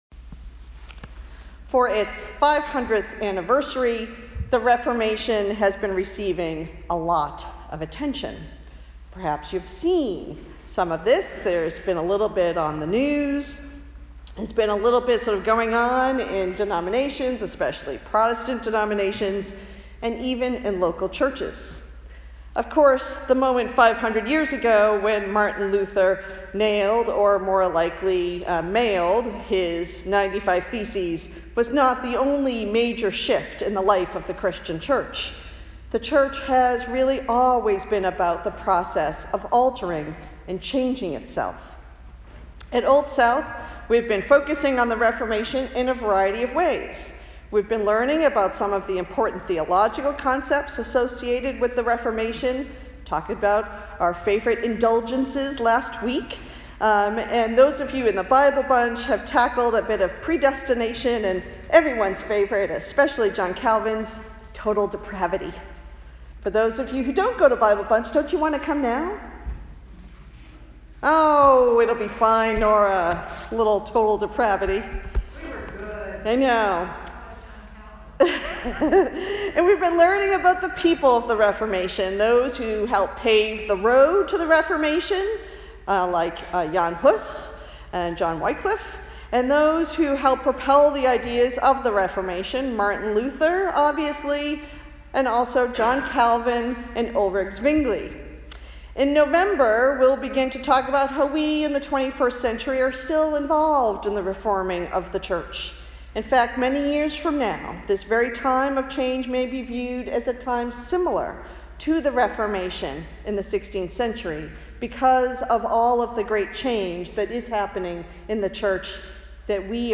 The Ongoing Work of the Reformation – Old South Congregational Church, United Church of Christ